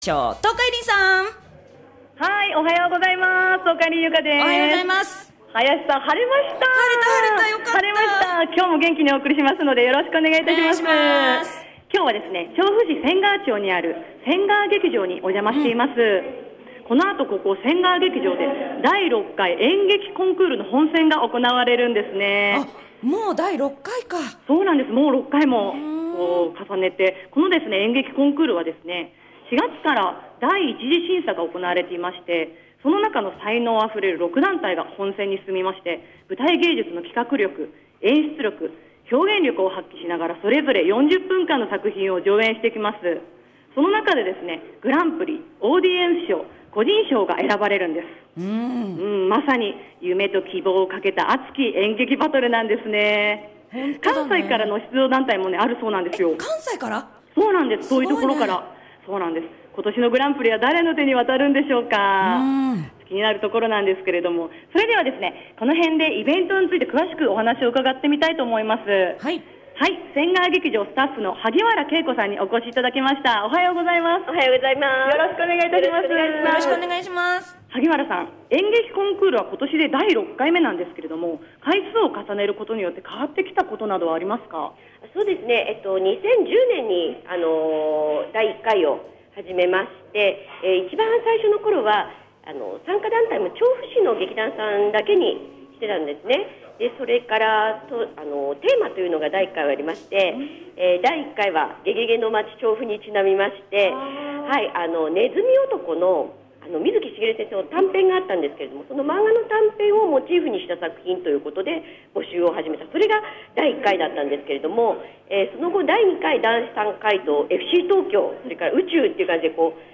せんがわ劇場へ！！